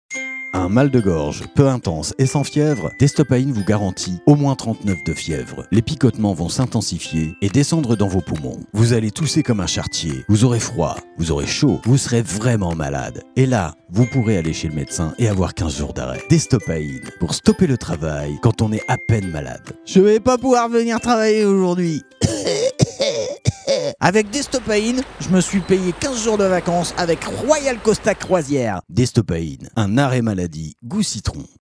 Fausses Pubs RADAR parodies publicités Fausses pubs